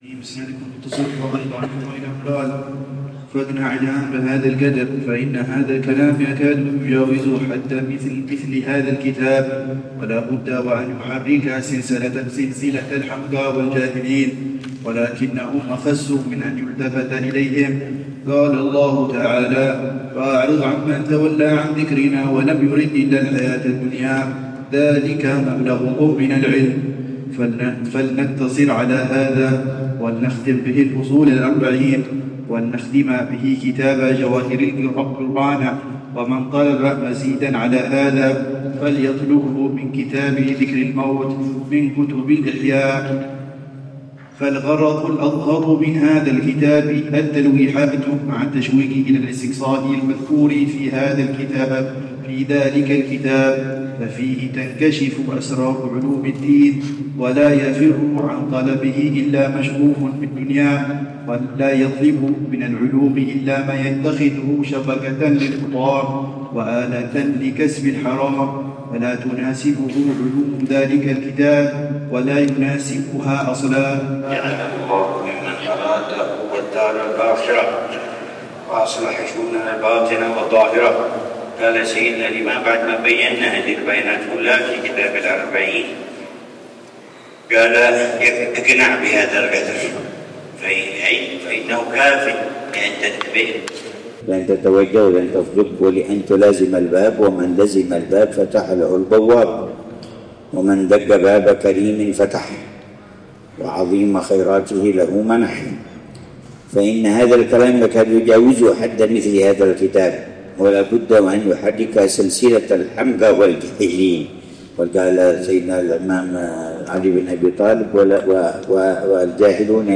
في مسجد الحسين بن طلال، عمّان، ضمن دروس الدورة العلمية في موسم شهداء مؤتة الأبرار، الأردن